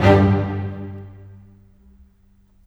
Rock-Pop 07 Strings 04.wav